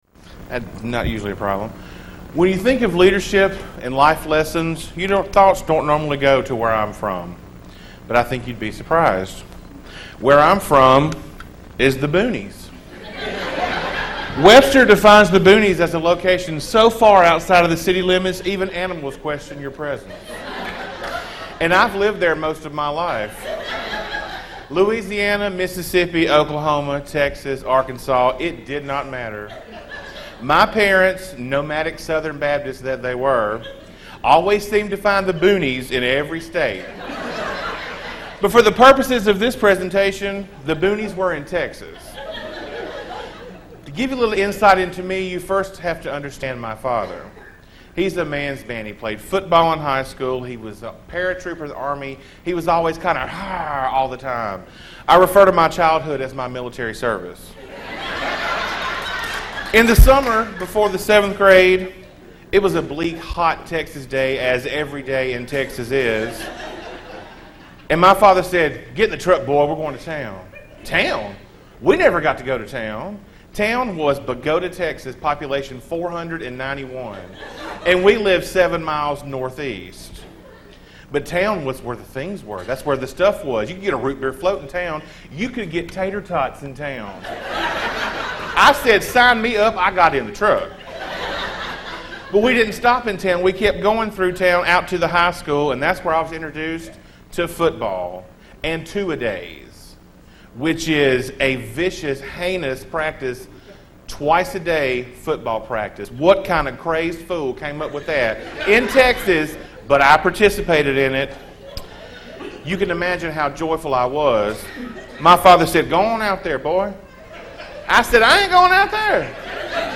Funny Leadership Speech